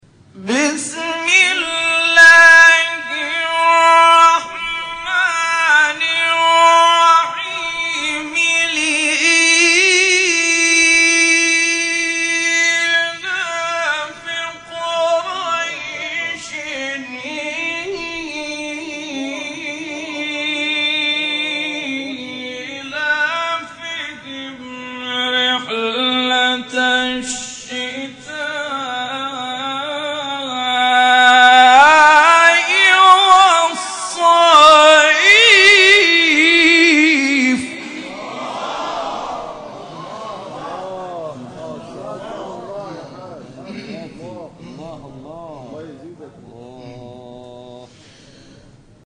شبکه اجتماعی: مقاطع صوتی از قاریان ممتاز کشور را می‌شنوید.